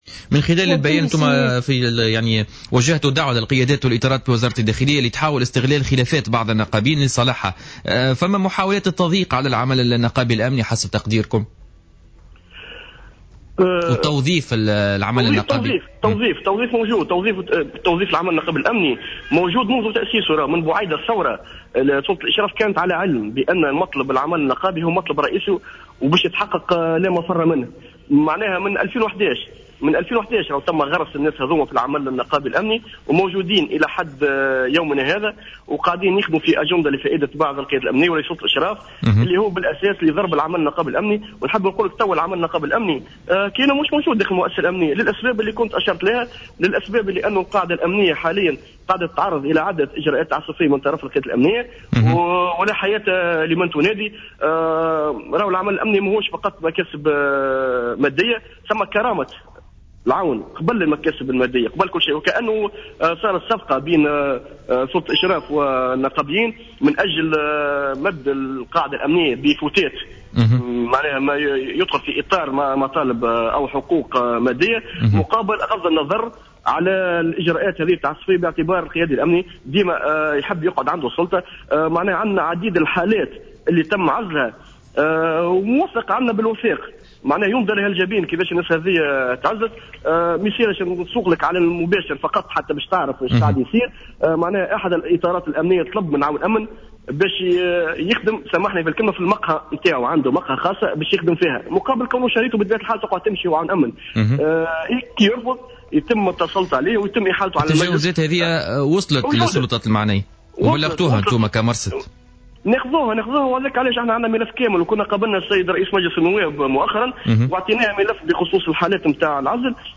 تصريح للجوهرة أف أم اليوم خلال حصة بوليتيكا